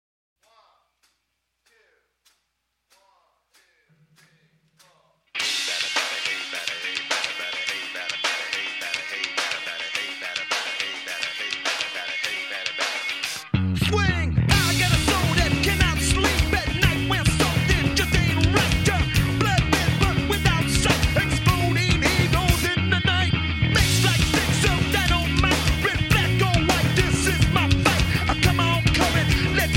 Alternative Rock / Funk Rock